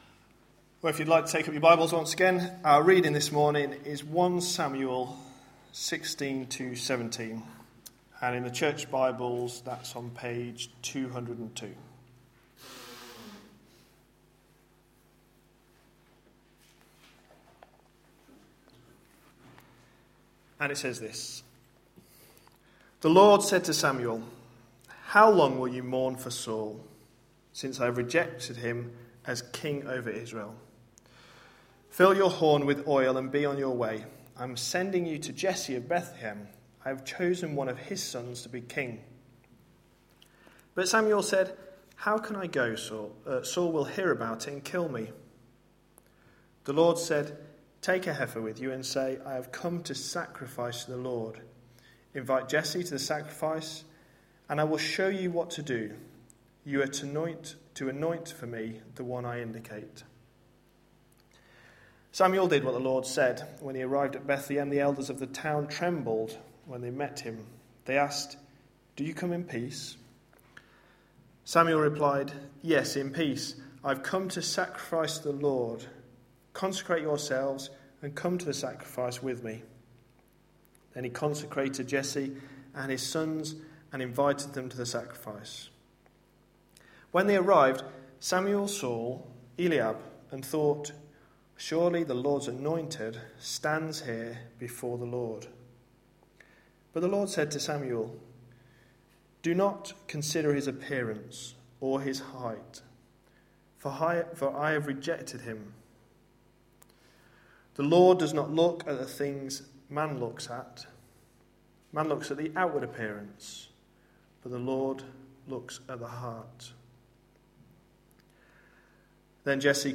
A sermon preached on 19th May, 2013, as part of our God's King? series.